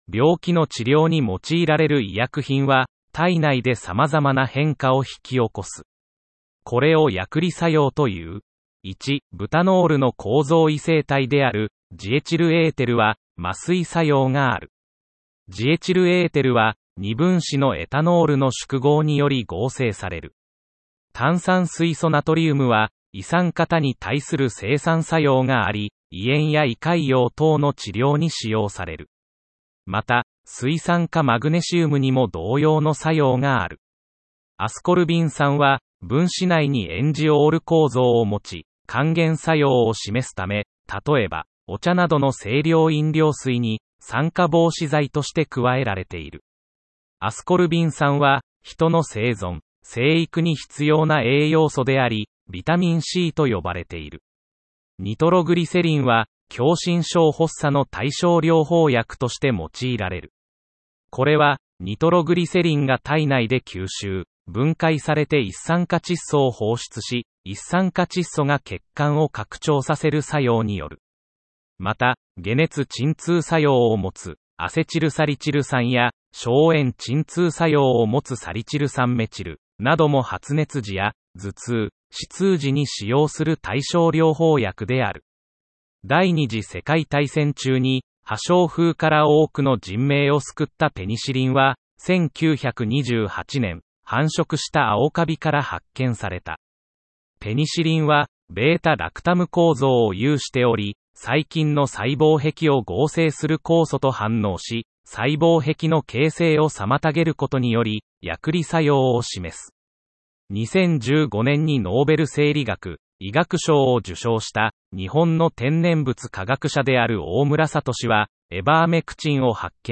問題文を朗読した音声データは『時間を有効活用したい！』という受験生のための画期的なアイテムです。
※問題文の朗読は、AIが読み上げたものを細かく調整しています。
多少、イントネーションがおかしい部分がありますが、その点はご了承ください。